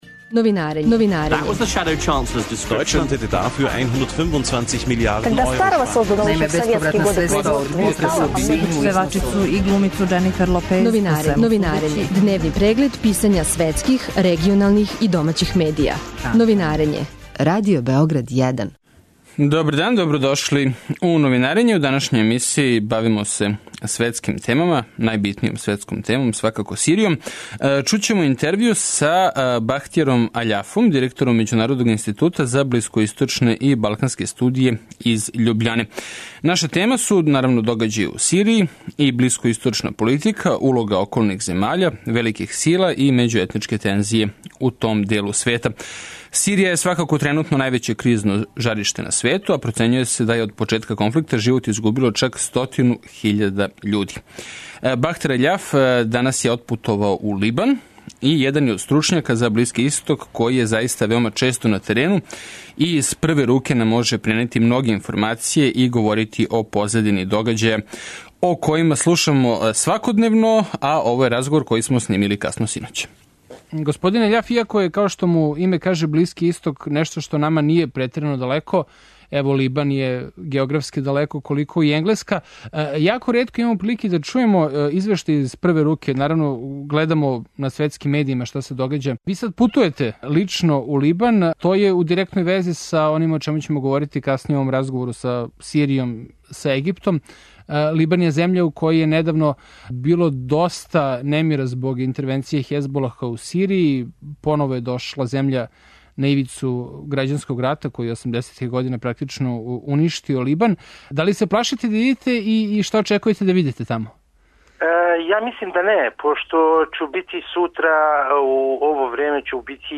Радио Београд 1, 09.40